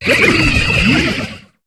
Cri de Prédastérie dans Pokémon HOME.